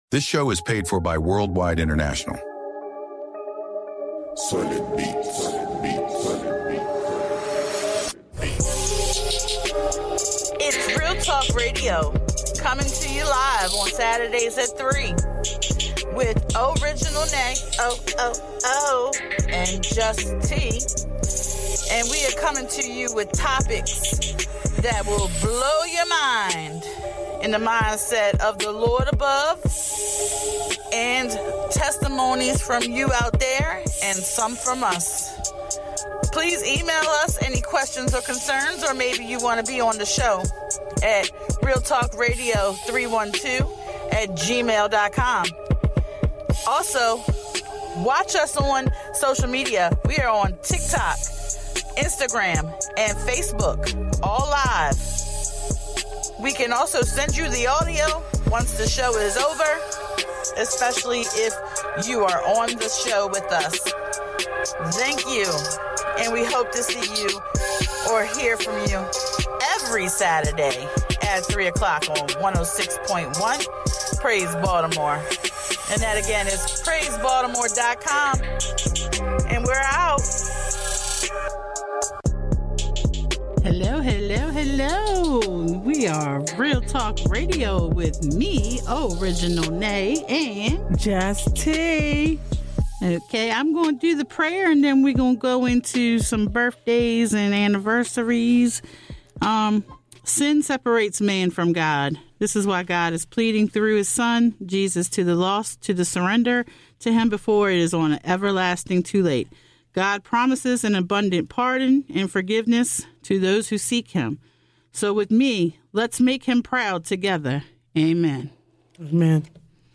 Please enjoy our radio show